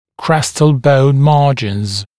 [‘krestəl bəun ‘mɑːʤɪnz][‘крэстэл боун ‘ма:джинз]край альвеолярного гребня